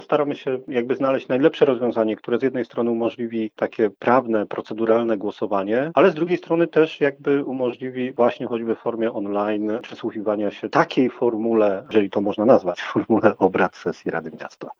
Trwają dyskusje na temat formy bądź ewentualnej zmiany terminu posiedzenia – mówi Piotr Barczak, przewodniczący Rady Miasta.